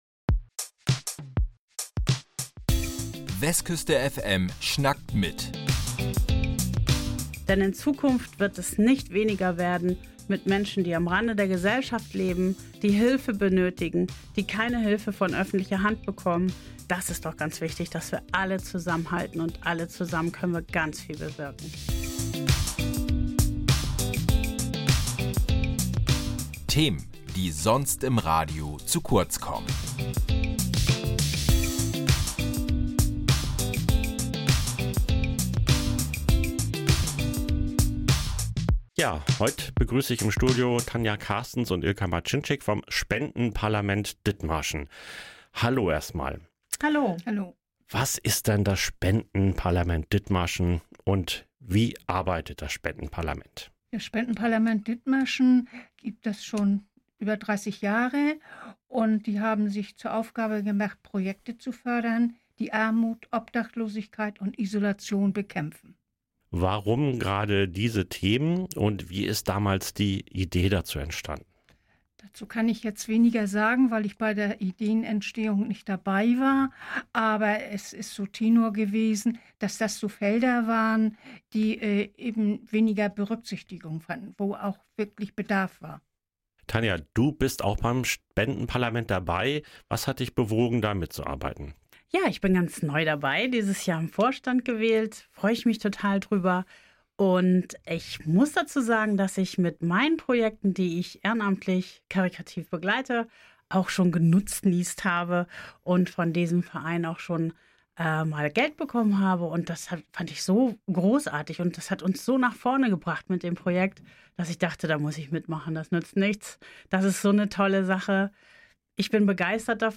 Darüber sprechen wir heute mit zwei Vorstandsmitgliedern.